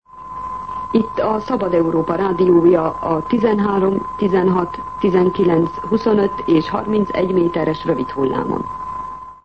Frekvenciaismertetés